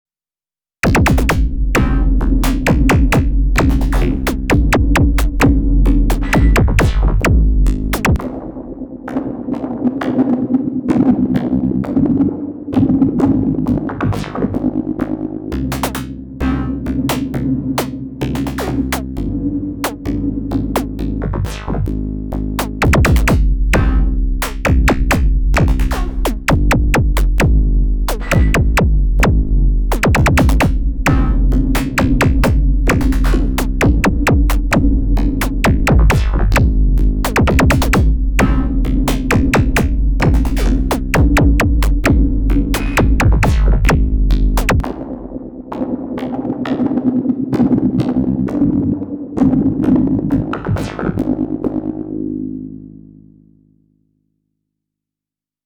Two different directions, but still EDM realm
First up, applied square wave LFO to pitch for the kick. Its chunky.